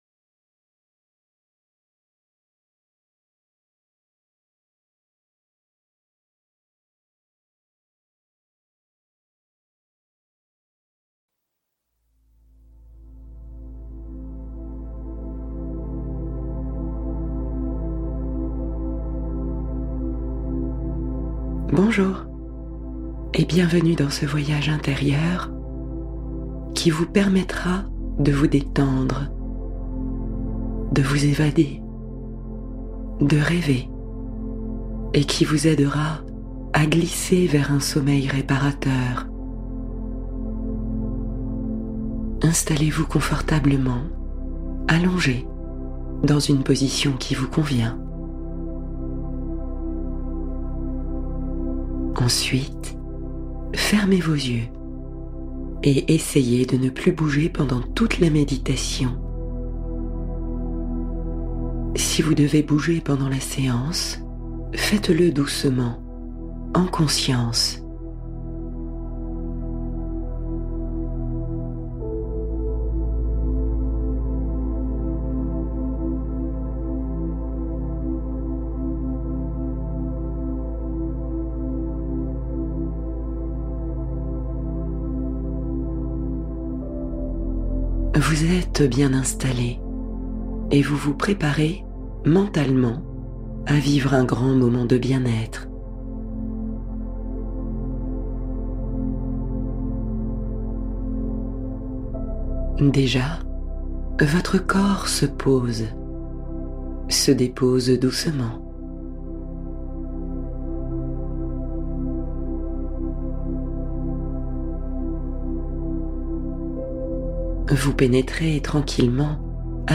Nuit Paisible : Scan corporel et murmures marins pour bien dormir